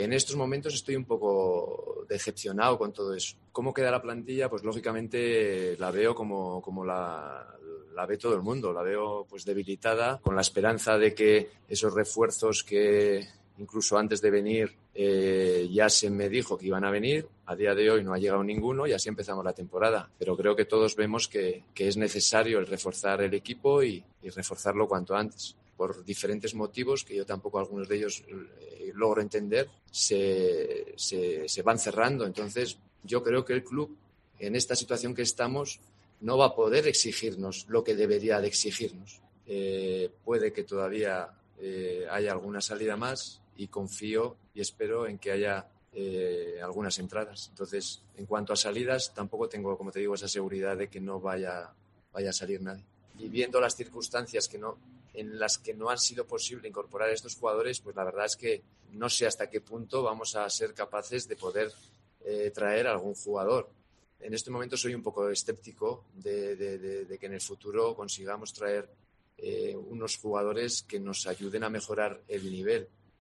El técnico navarro ha comparecido en sala de prensa junto a Paco López en la previa del derbi. Enfadado, triste y decepcionado por no tener refuerzos en su plantilla
AUDIO. Esto es lo que ha dicho Javi Gracia